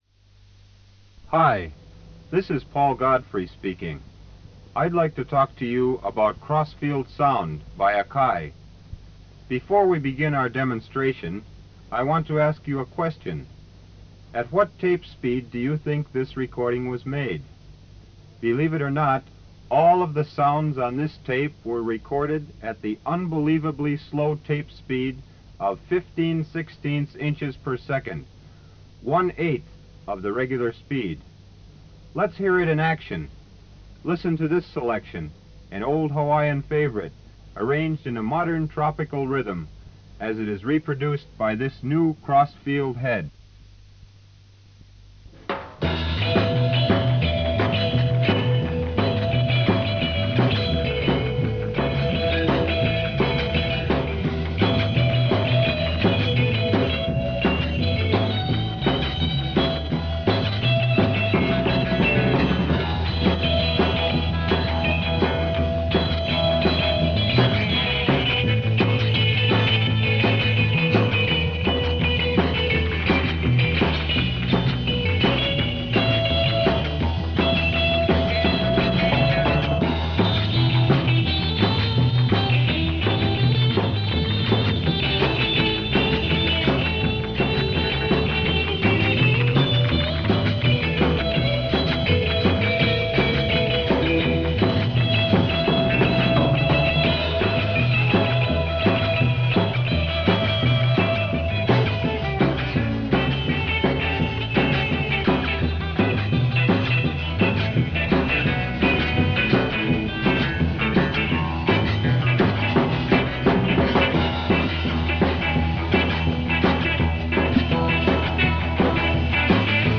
akai-demonstration-tape-recorded-at-one-and-seven-eighths-inches-per-second.mp3